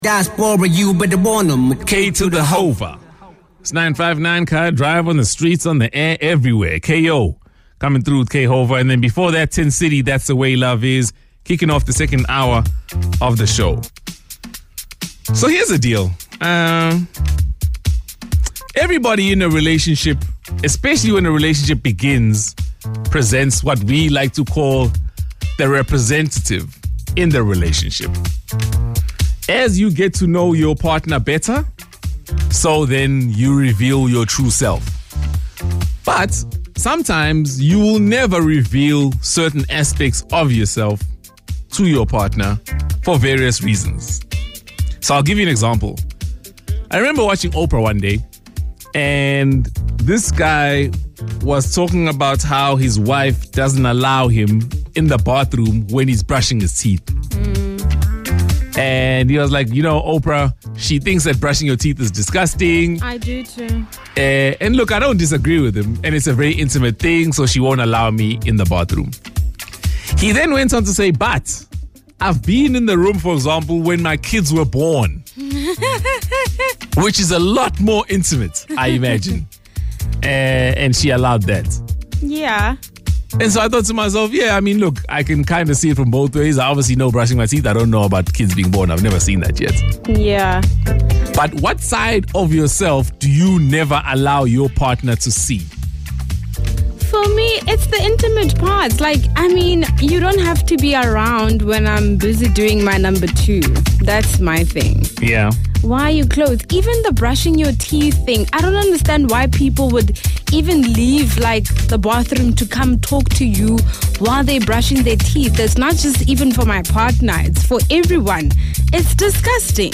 Listen to the team share their relationship boundaries: